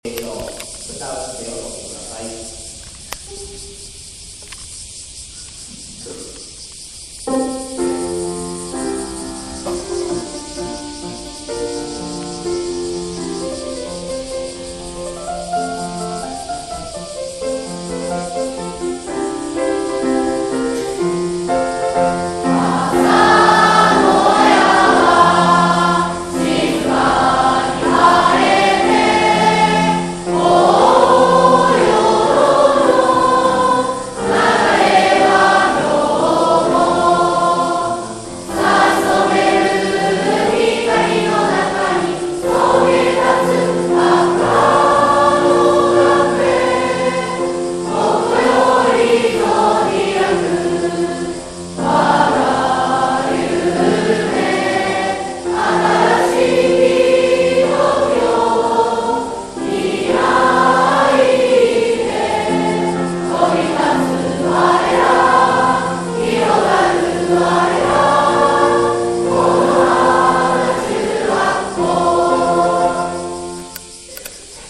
7月20日(水) 第1学期終業式
梅雨明けを喜ぶように響く蝉の声にも負けじと、 此花中学校1学期の終わりを告げる校歌が体育館に響きました。 平成28年度第1学期終業式校歌 終業式は、ひとつの区切りです。